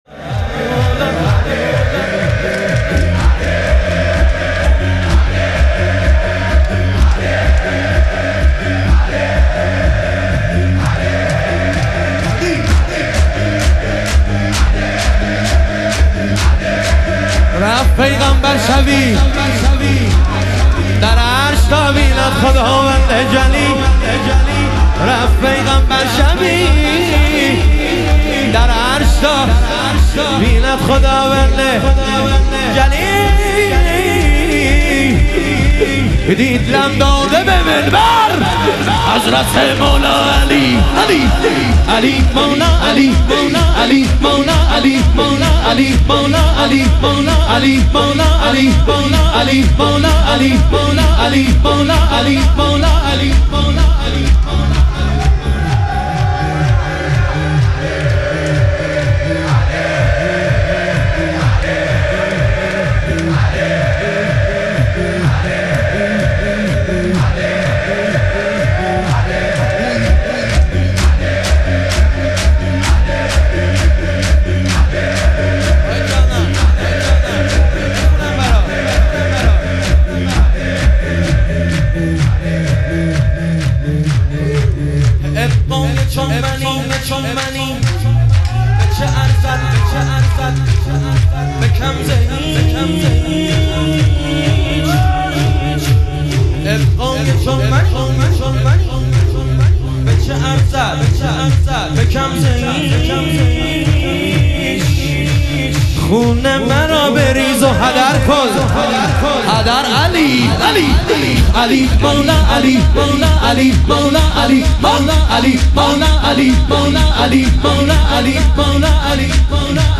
شهادت حضرت مسلم علیه السلام - شور